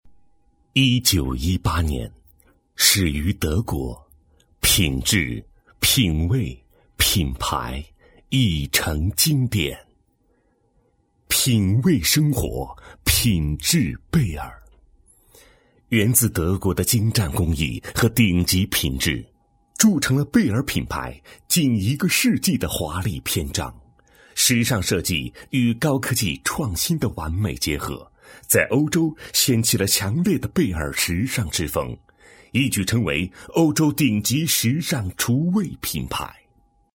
男声配音
低沉韵味